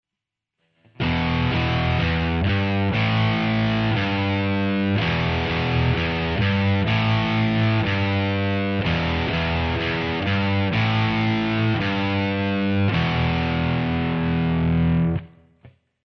Power chord exercise 3 [MP3]
powechord Ex. 3.mp3